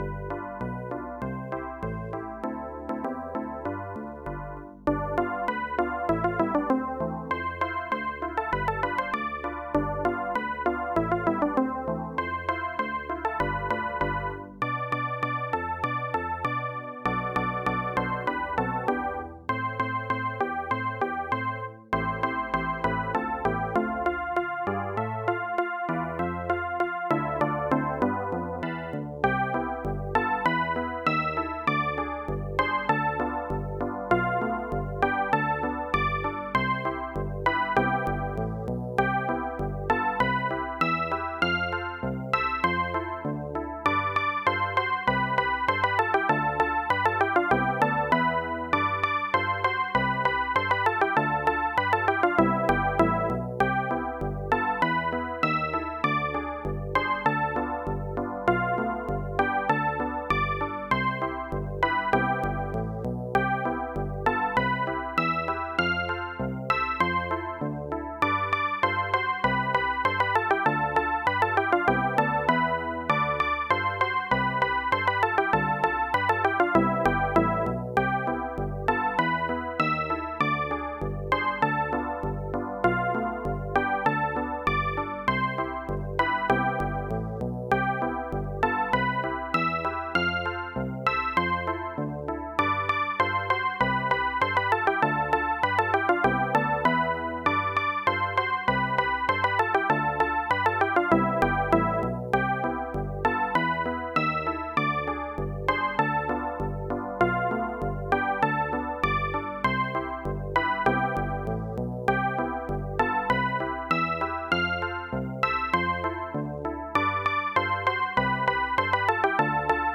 AdLib MUS